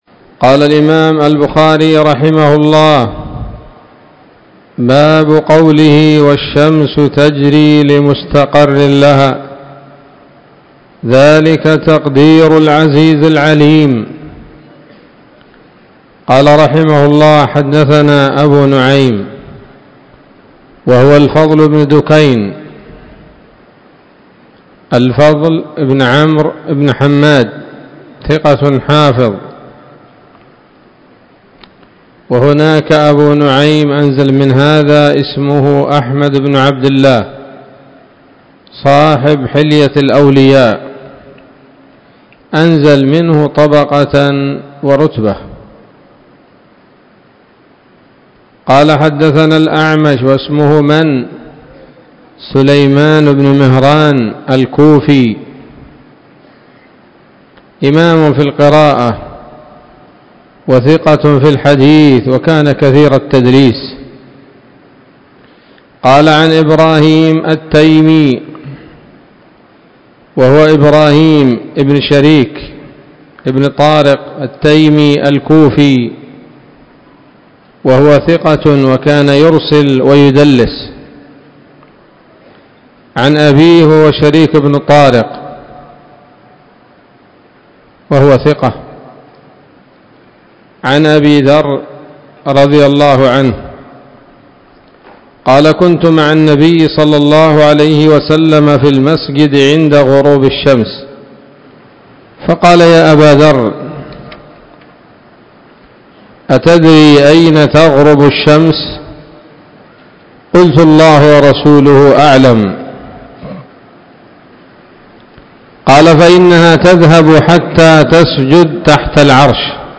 الدرس الثاني عشر بعد المائتين من كتاب التفسير من صحيح الإمام البخاري